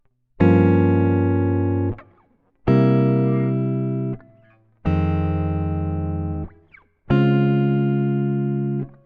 Before moving on to learning these jazz guitar chord voicings types with a 4th string root, combine the 6th and 5th string chords to voice the chords closer together.
Try beginning on the 6th string for the Gm7b5 chord and then move to the closest voicing of the C7 chord on the 5th string (3rd fret) when you change.